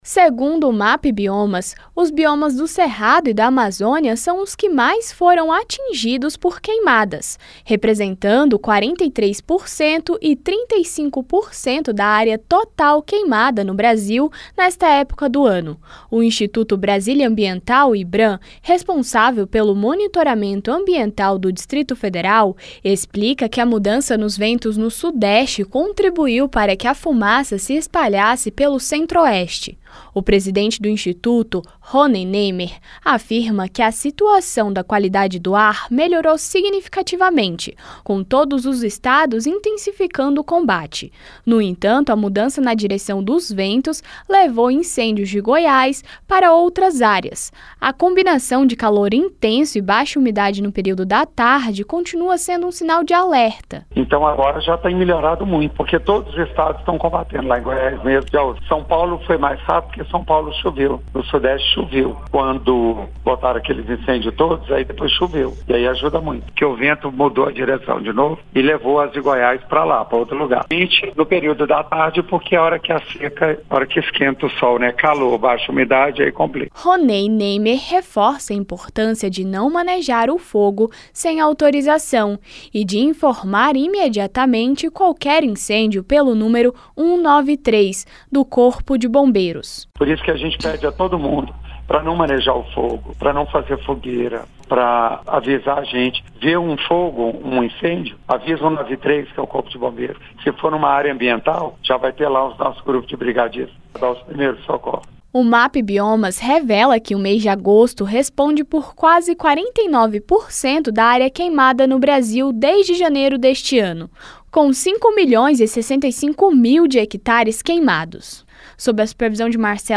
Presidente do IBRAM fala sobre as queimadas no Centro-Oeste